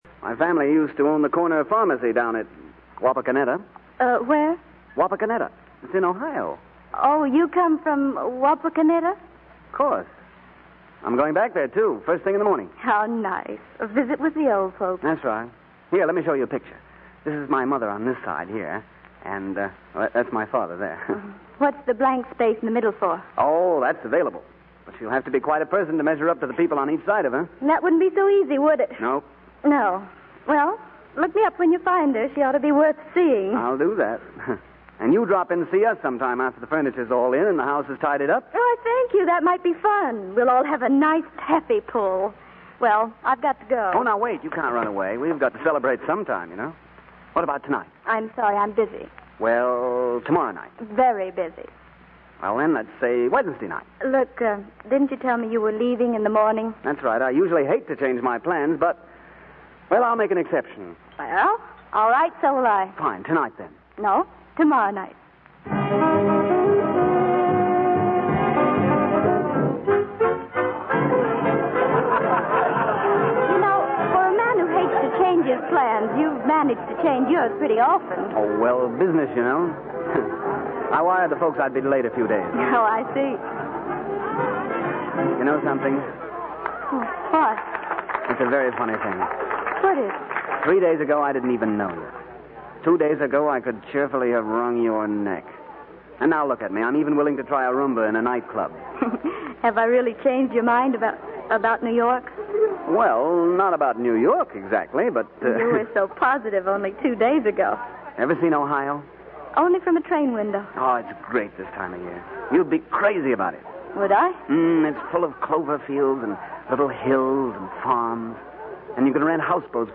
Old Time Radio Show